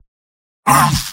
Robot-filtered lines from MvM.
Spy_mvm_painsharp02.mp3